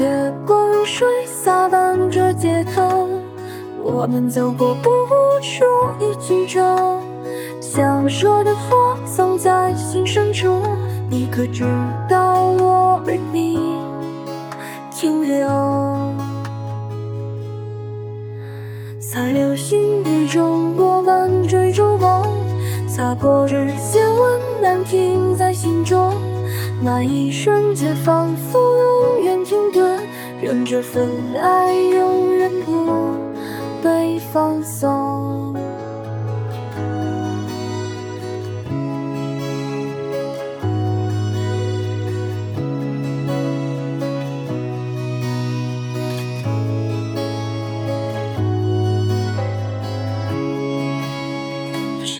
大家听DEMO可以感受到，最终出来的音乐跟最初人类作曲家的动机旋律是完全匹配的。